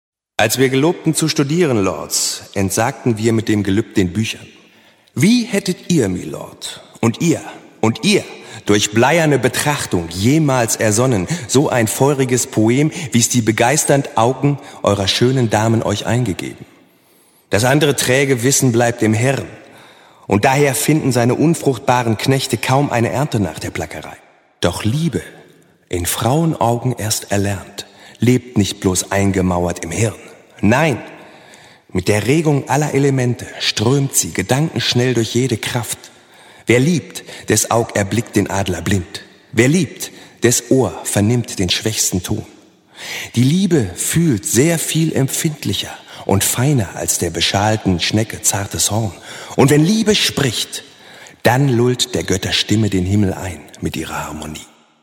Werbung Peugeot